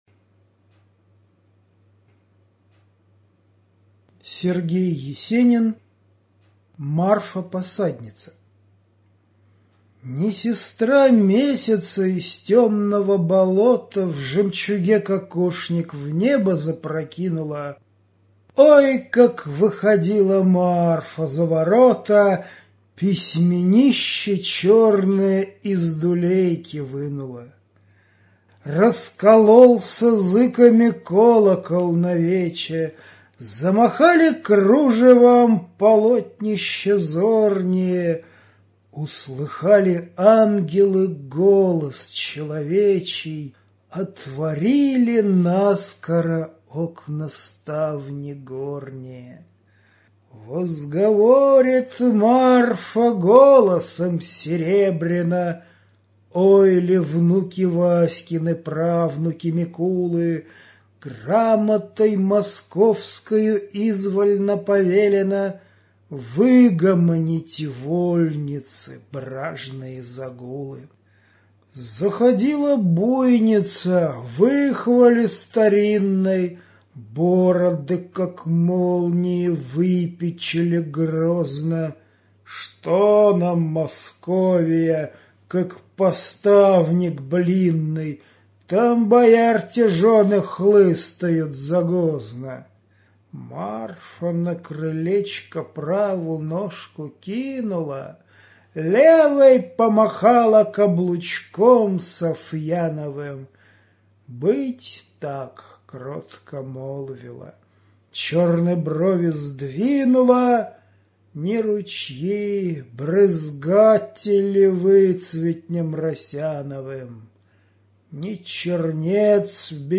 Студия звукозаписиНовгородская областная библиотека для незрячих и слабовидящих "Веда"